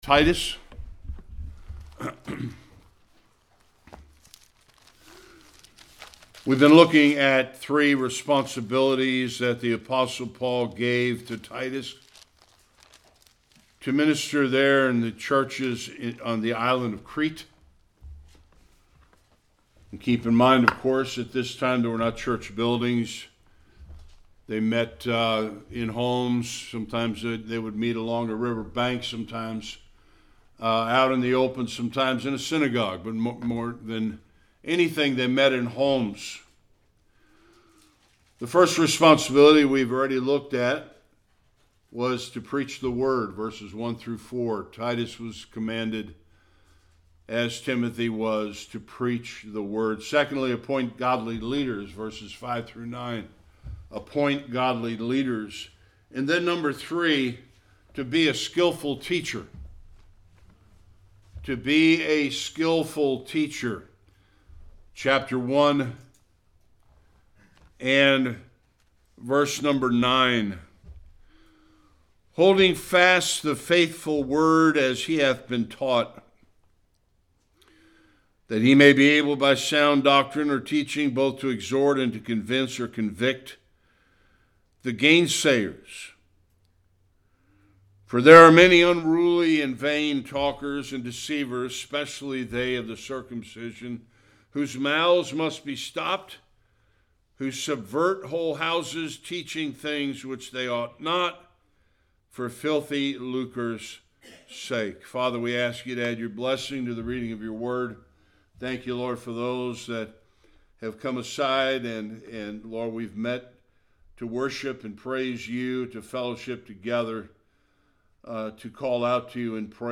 9-16 Service Type: Sunday Worship Titus was instructed to hold fast the faithful Word.